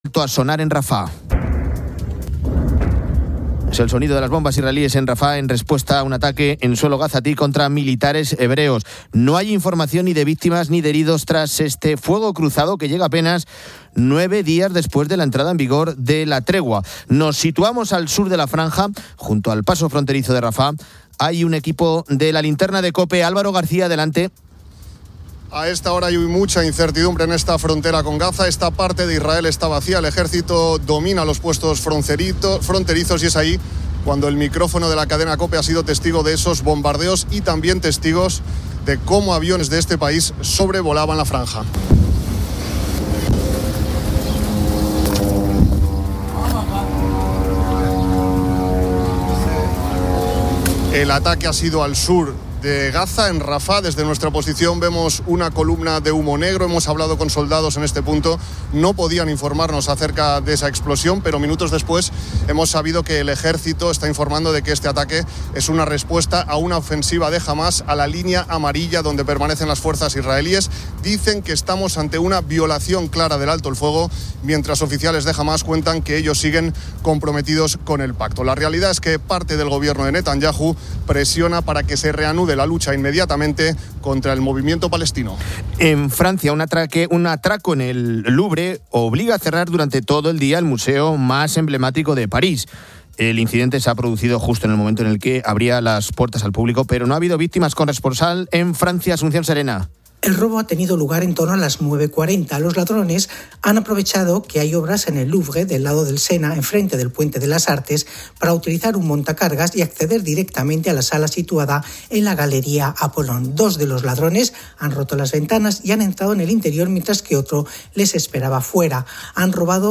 El Louvre cierra en París por el robo de joyas. El Papa canoniza a siete santos, entre ellos un médico venezolano. COPE debate sobre la educación.